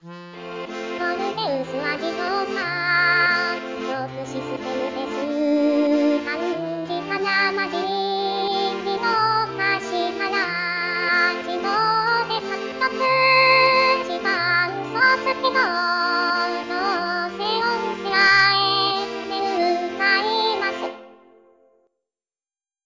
「スタイル：「雪うさぎ」風」
自動で作曲し、伴奏つきの
合成音声で歌います。